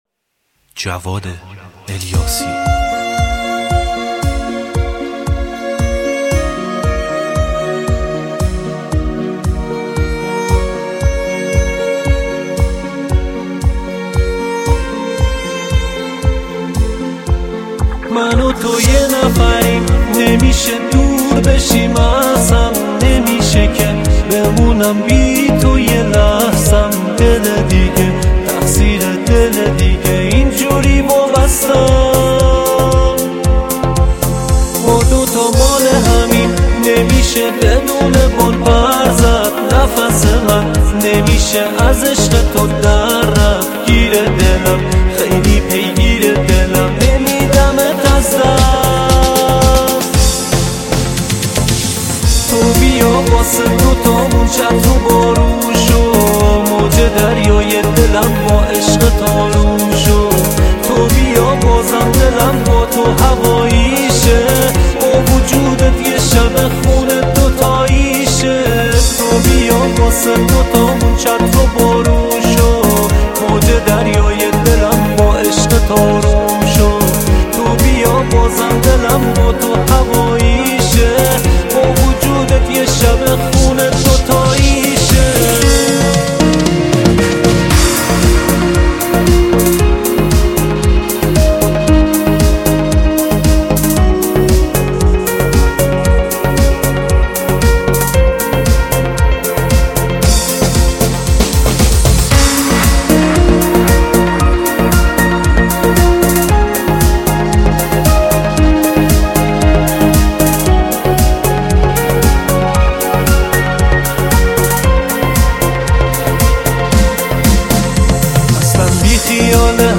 آهنگهای پاپ فارسی
با کیفیت خوب و عالی